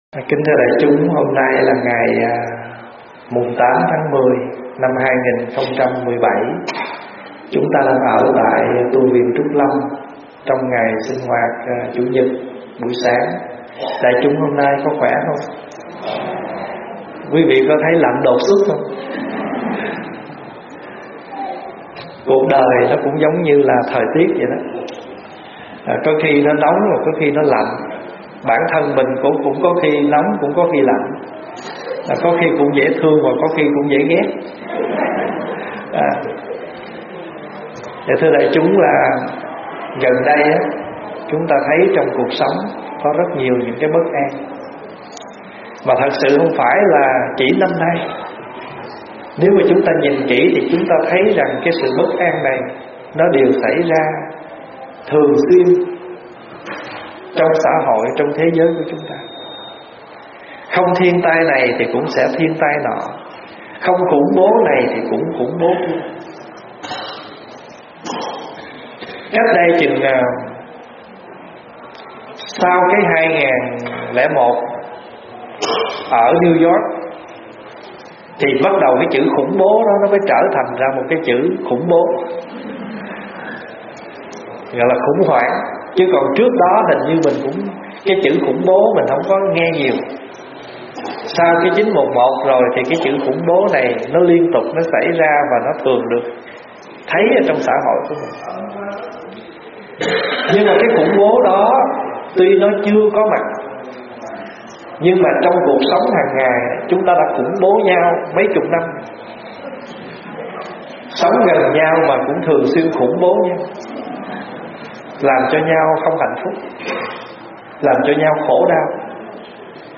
Nghe mp3 thuyết pháp 4 Điều Phật Không Sợ
giảng tại tu viện Trúc Lâm